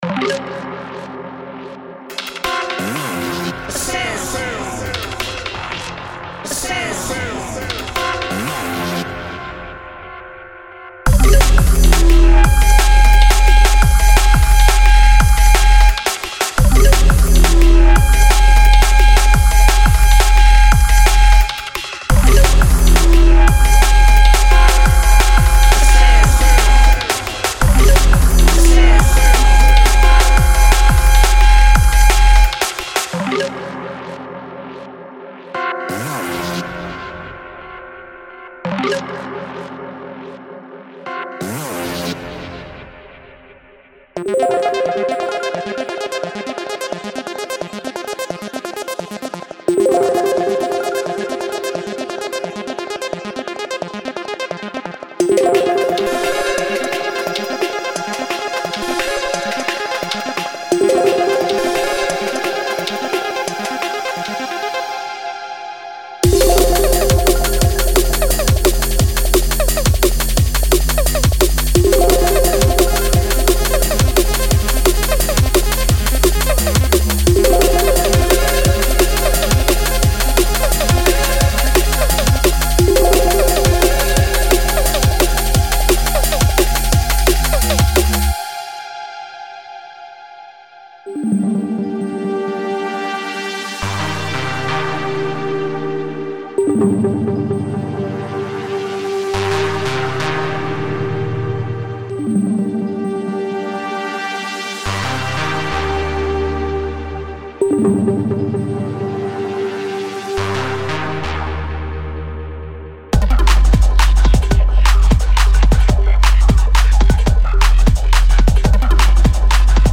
•26个低音循环
•19个合成器循环